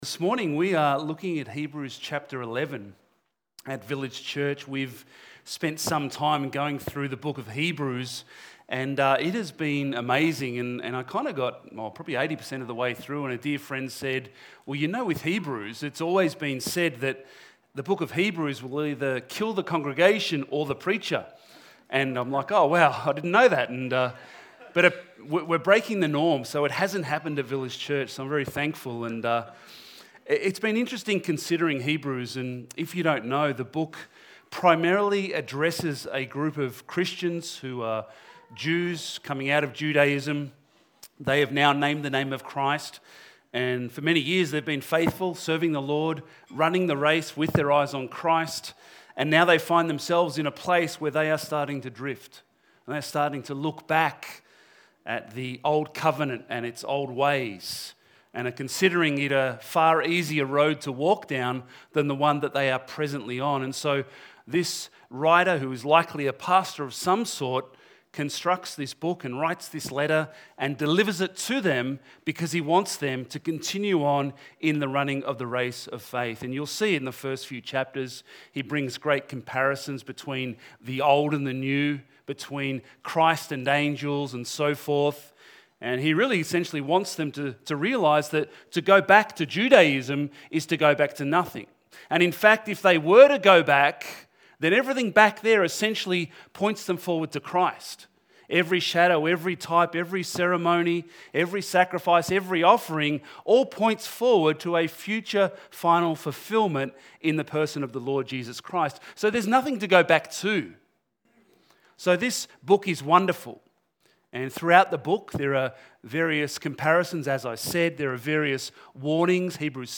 Church-Sermon-230225.mp3